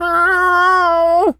cat_meow_05.wav